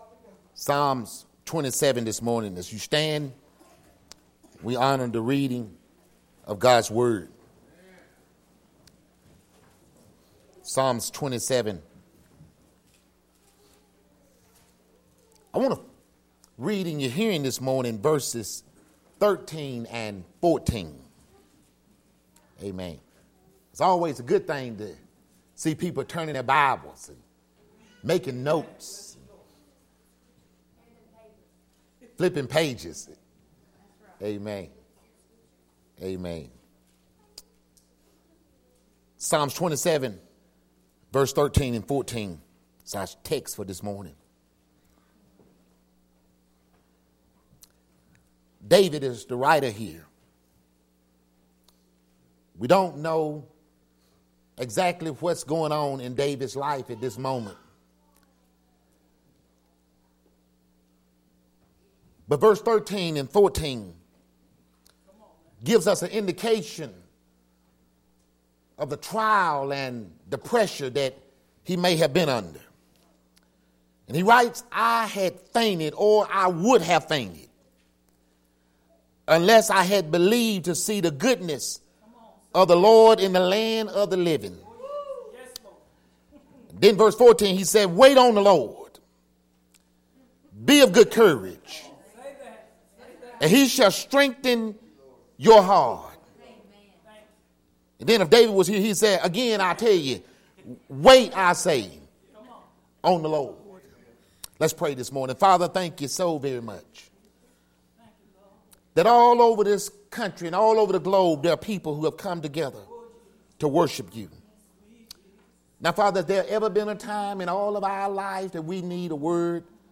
Solid Rock Baptist Church Sermons